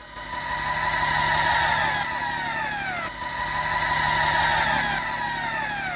synthFX02.wav